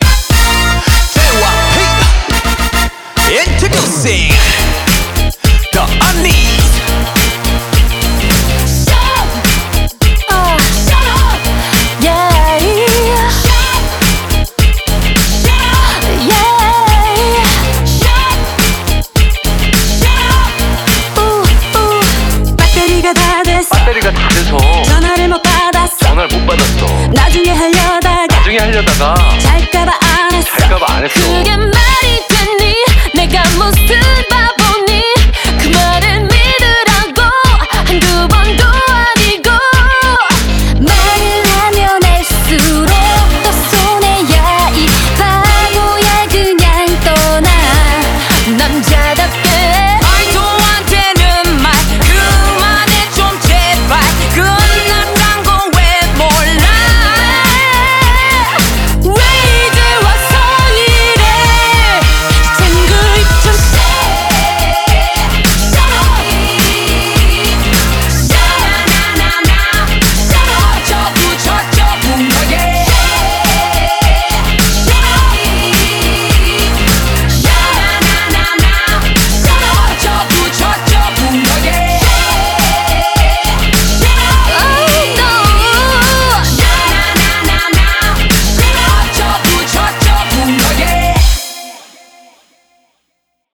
BPM105